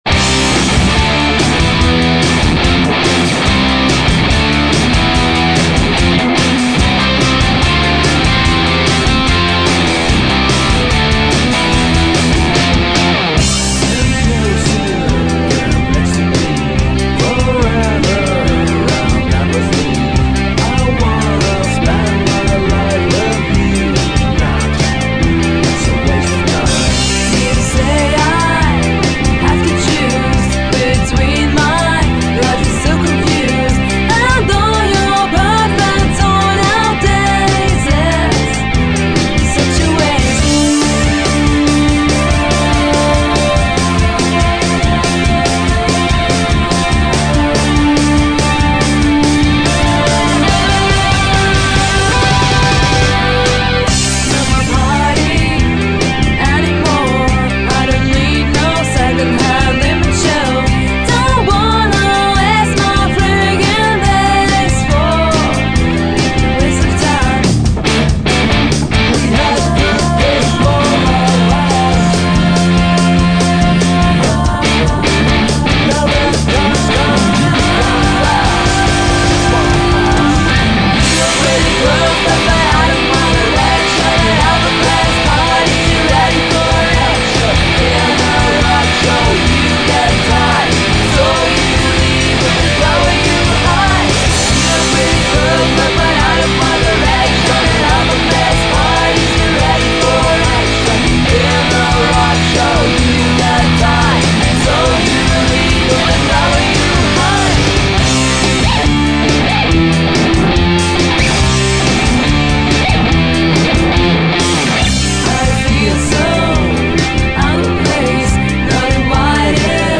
Bass
Vocals